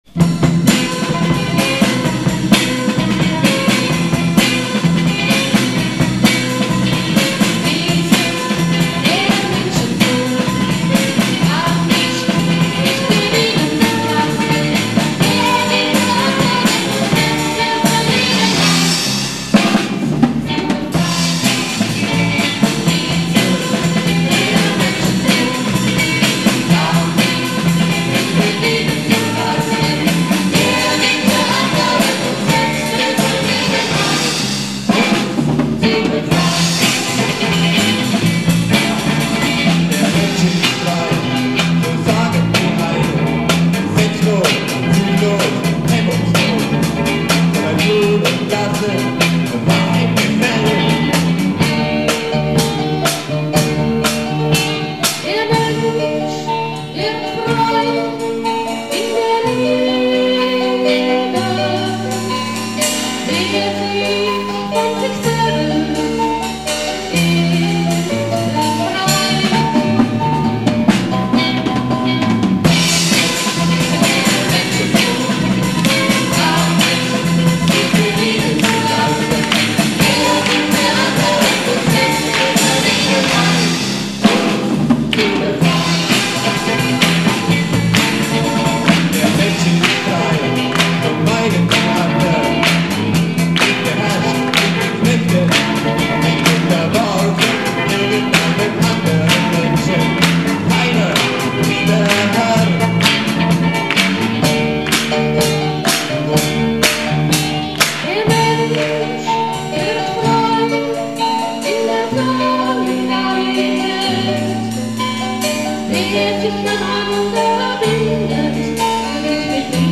alle Aufnahmen sind "live" in der Kirche mitgeschnitten und deshalb keine CD-Qualität